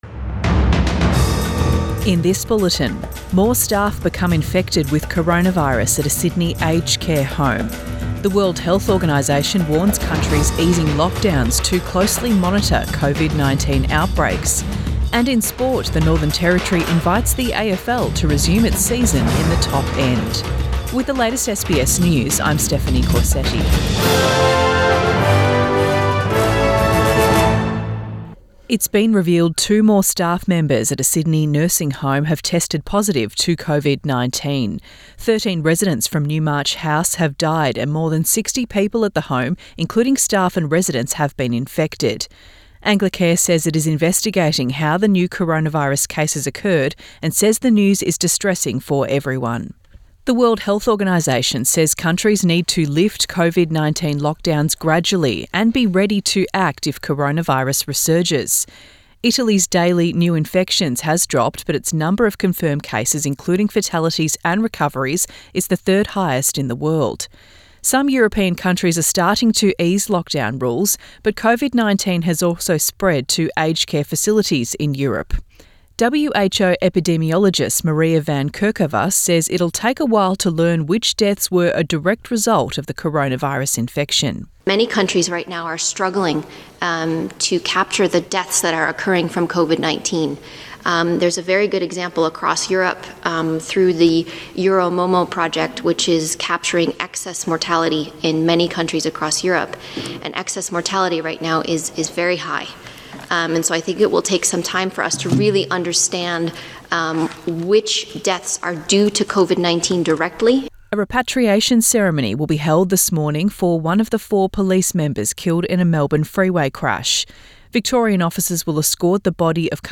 AM bulletin 2 May 2020